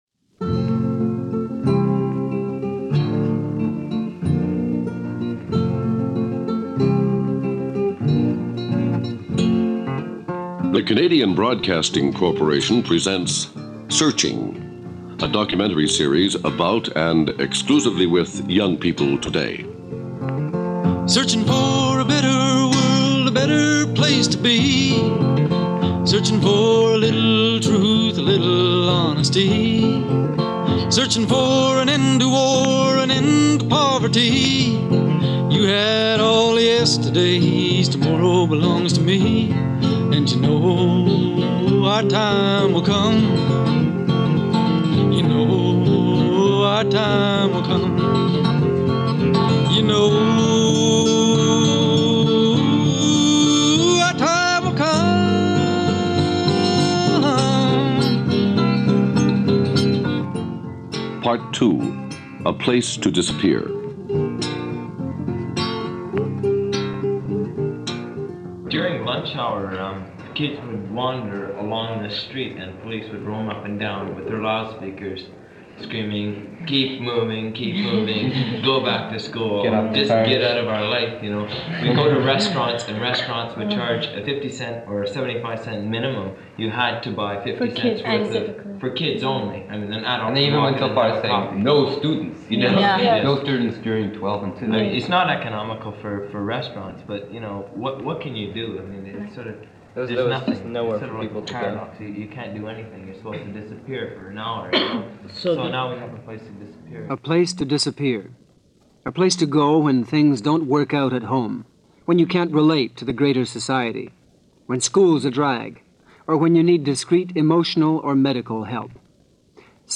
It’s all done through the voices of the participants themselves and the world as viewed from their eyes. There is also the somewhat quaint and precious music that pops in every so often – and the thundering, detached voice of the CBC announcer.